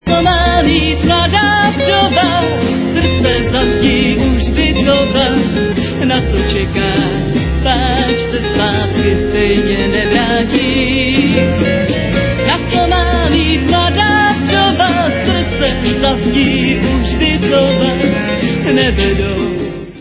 Renesančně laděnými autorskými písněmi